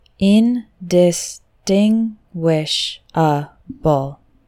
Slow: